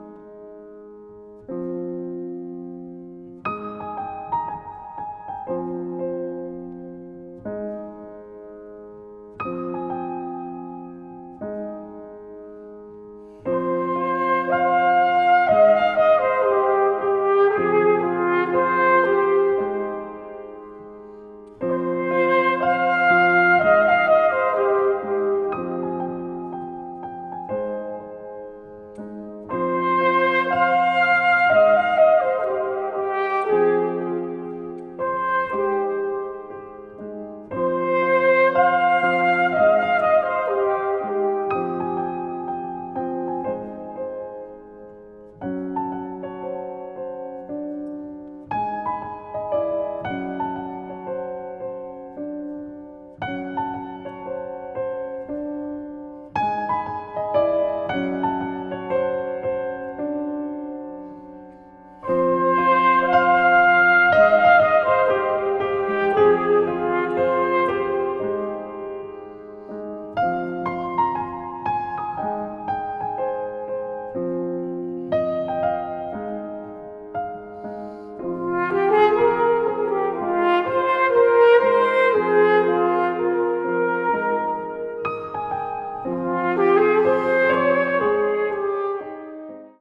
french horn, flugelhorn
violinchello
chamber music   contemporary   contemporary jazz   deep jazz